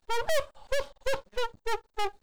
snd_boss_laugh.wav